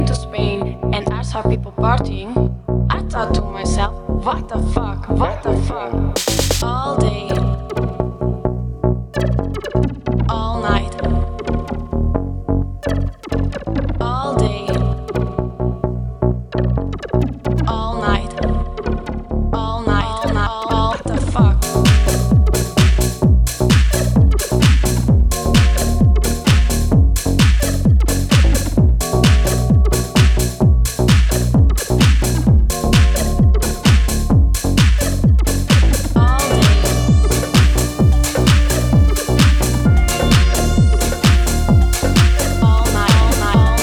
electric house tracks